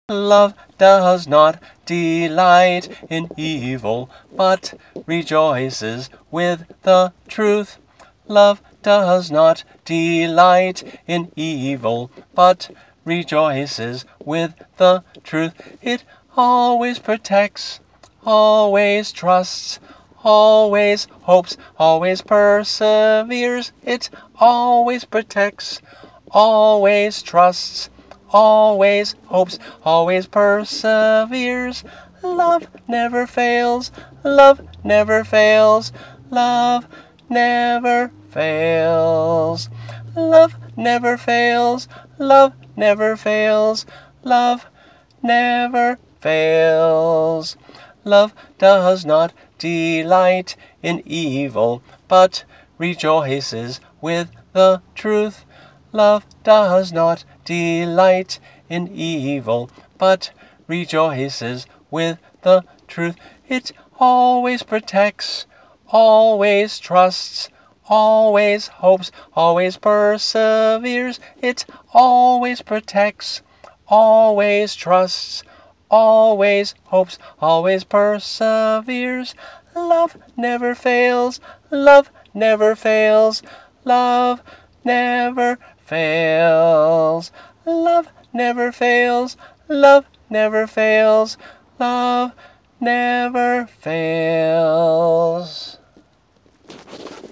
3-part round
MP3 - voice only